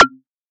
edm-perc-20.wav